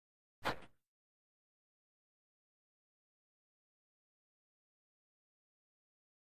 FootstepsSnowCrnc WES095001
Snow Hiking; Single Crunch Footstep In Snow.